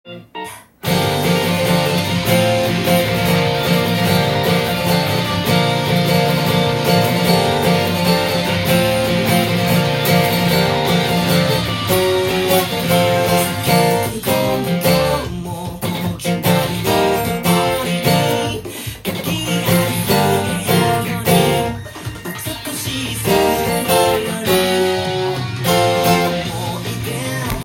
ギターが目立つカッコいい曲です！
音源にあわせてギターで弾いてみました
３カポにするとローコード主体で弾くことが出来ます。
８分音符や１６分音符が混ざっていることに気が付きます。